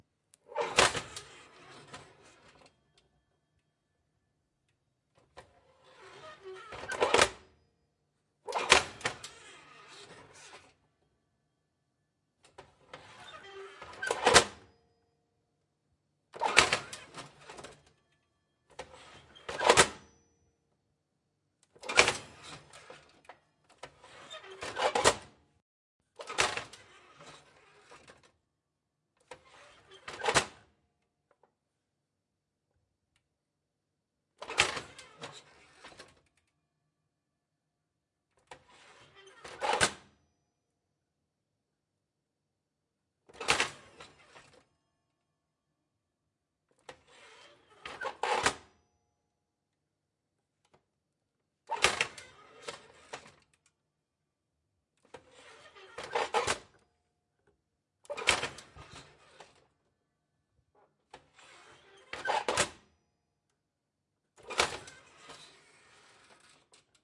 老式电炉 " 老式电炉，烤箱门铰链吱吱响 2
描述：从旧电炉，金属铰链，门和开关记录的声音。
标签： 厨房 炉灶 厨师 烤箱 声音效果 开关 金属 烹饪 家庭 住宅 FX SFX
声道立体声